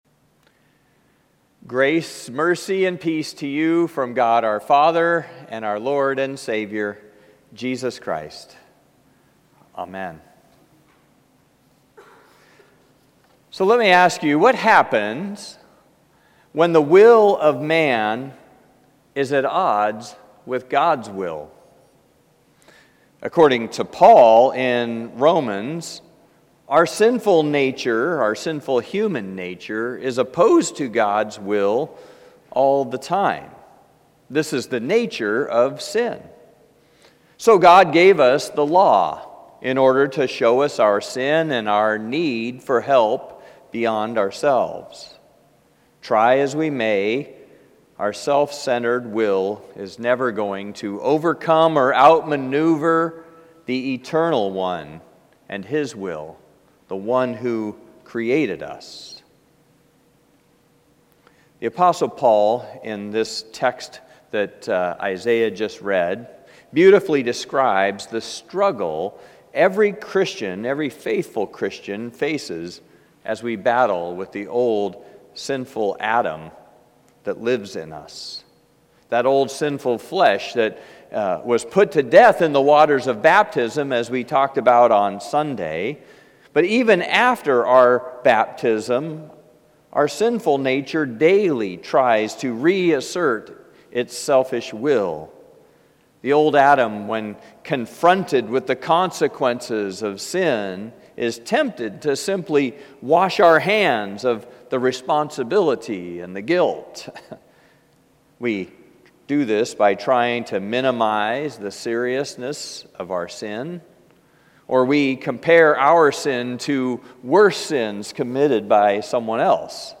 Places of the Passion Passage: Luke 23:13-25 Service Type: Special « You Have Already Died…Now What?